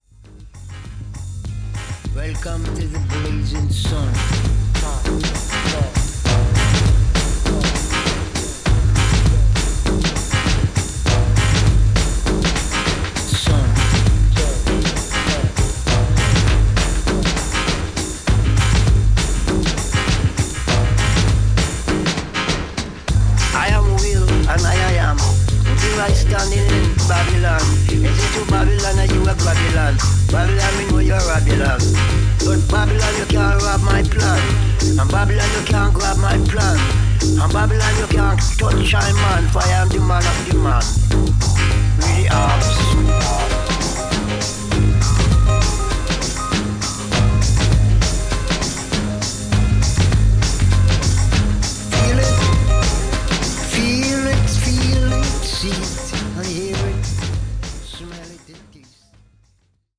スポークン・ワード